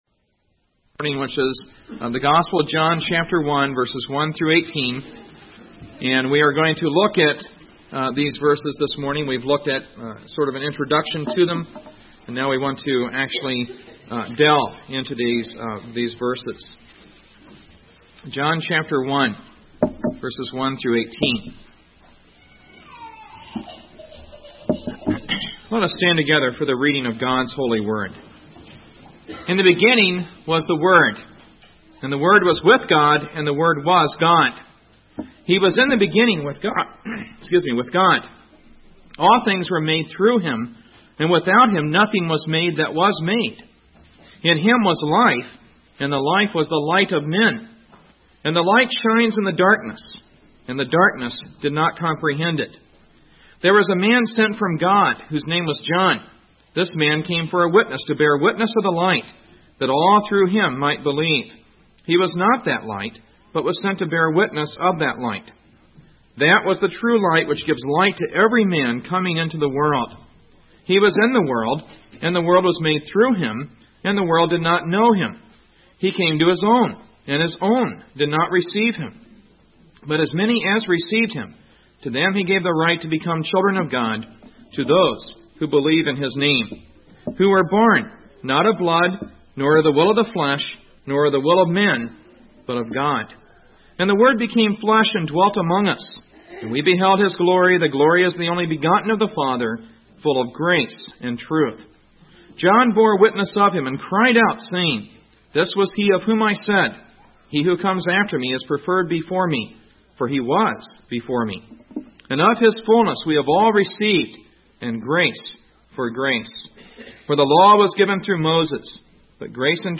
1999 Sermons